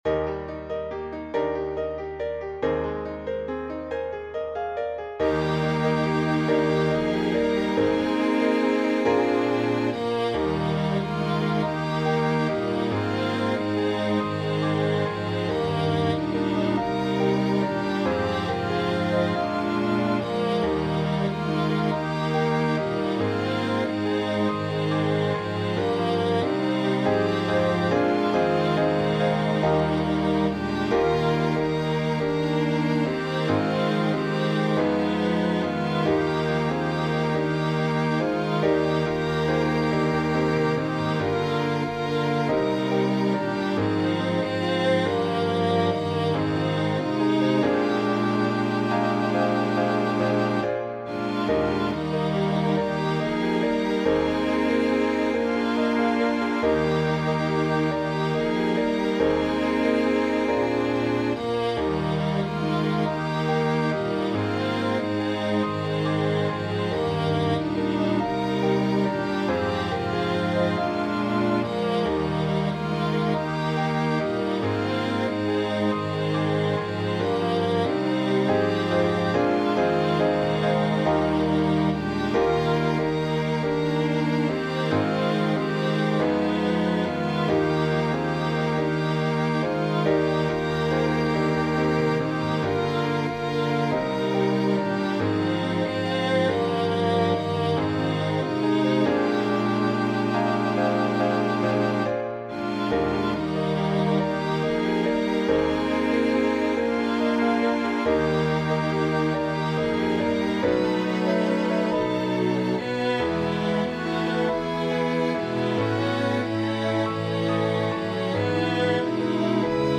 SATB
Accompaniment